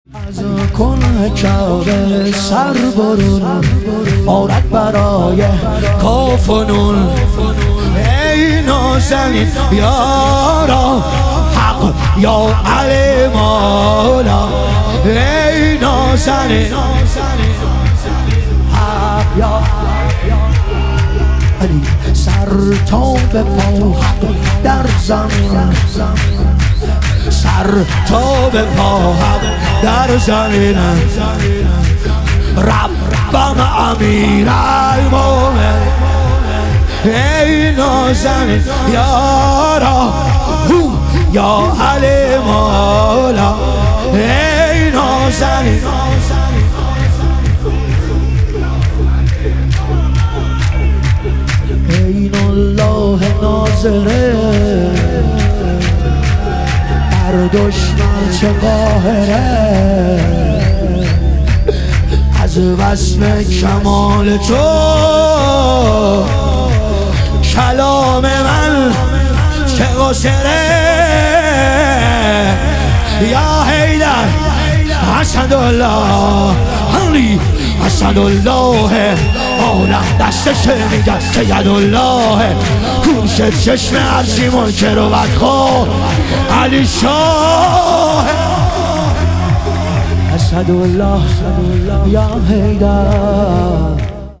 ظهور وجود مقدس حضرت رقیه علیها سلام - شور